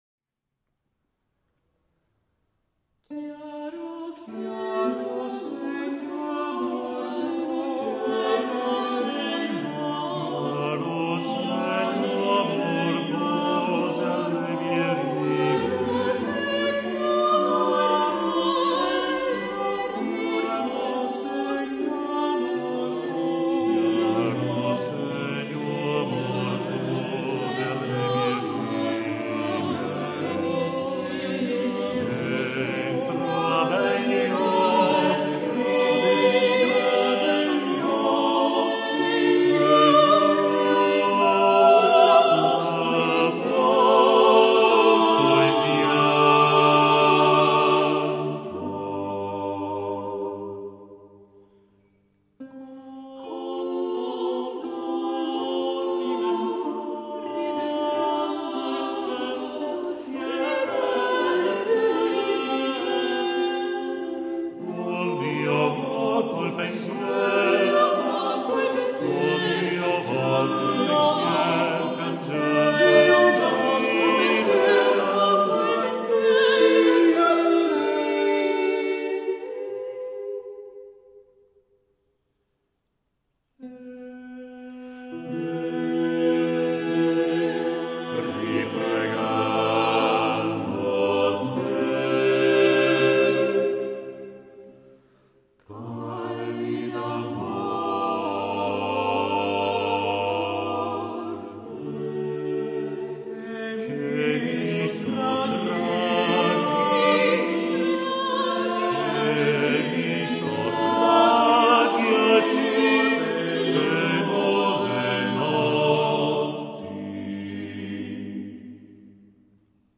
Luca Marenzio [1533-1559]
in Madrigali a 5 voci, Libro 9